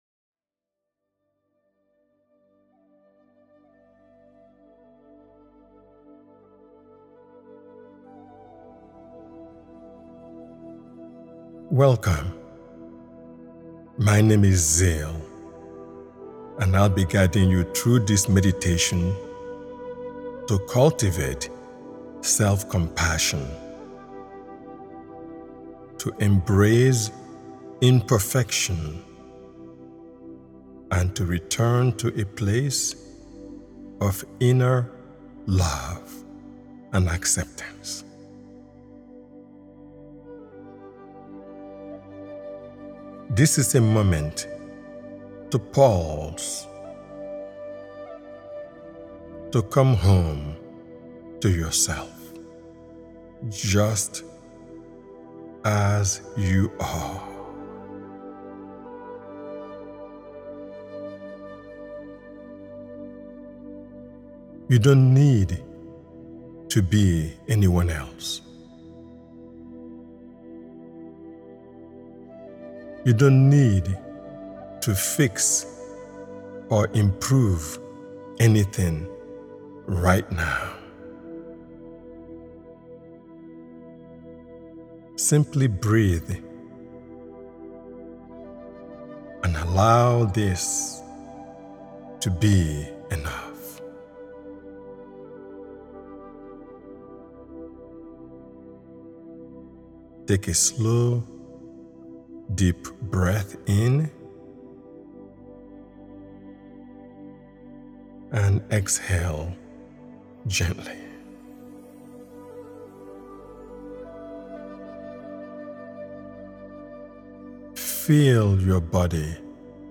Radical Acceptance: Coming Home to Yourself is a compassion-centered guided meditation designed to help you release self-judgment and reconnect with inner safety, kindness, and truth.